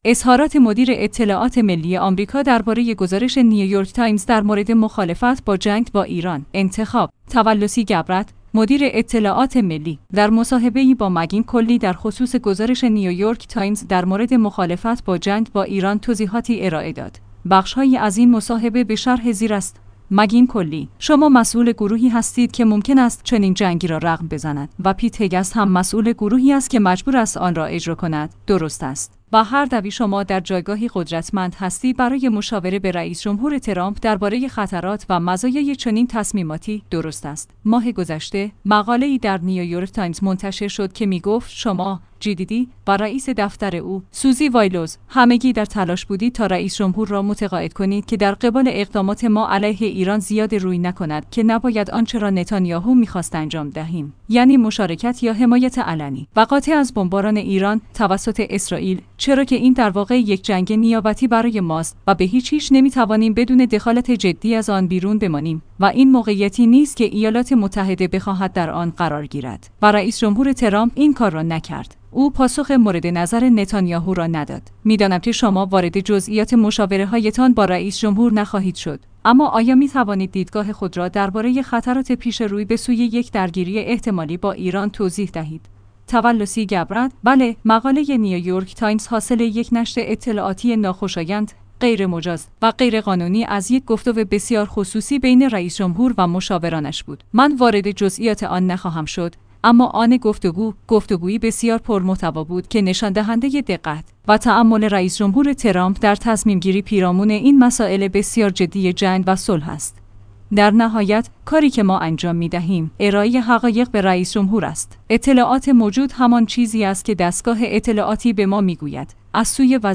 انتخاب/ تولسی گبرد، مدیر اطلاعات ملی، در مصاحبه‌ای با مگین کلی در خصوص گزارش نیویورک تایمز درمورد مخالفت با جنگ با ایران توضیحاتی ارائه داد.